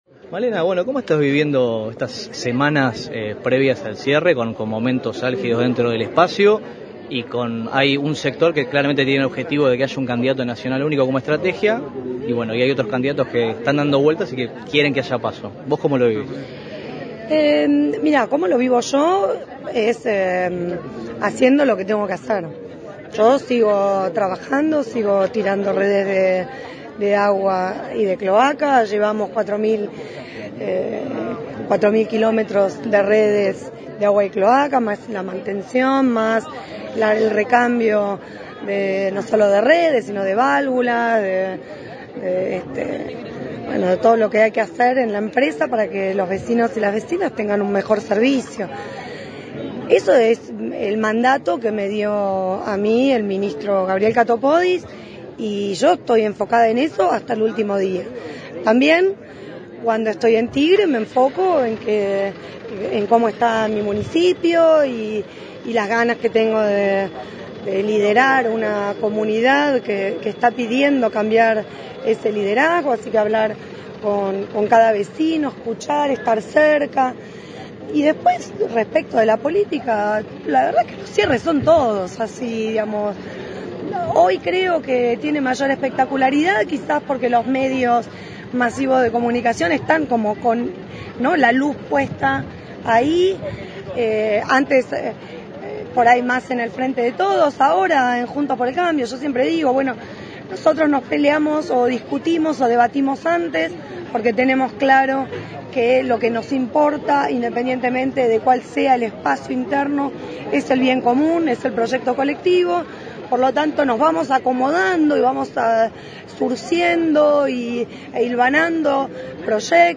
Al finalizar la jornada, Galmarini dialogó con Infowebnoticias y los medios presentes.